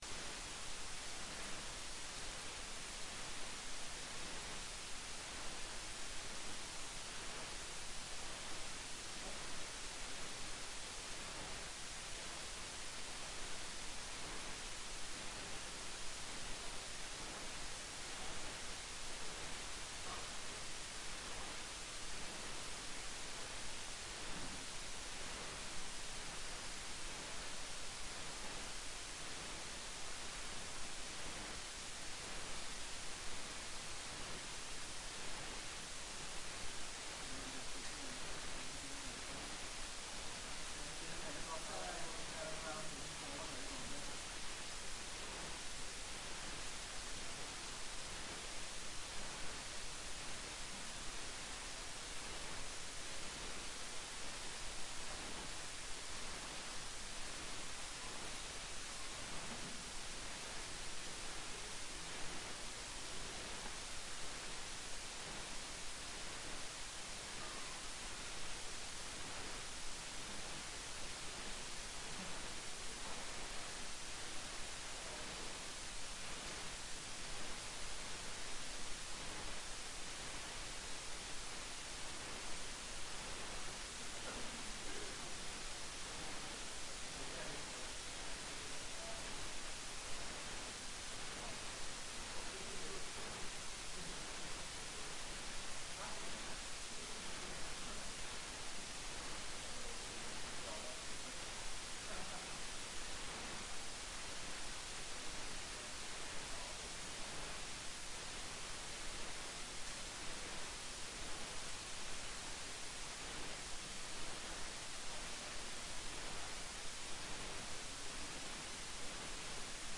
Forelesning 4.3.2020
Rom: Store Eureka, 2/3 Eureka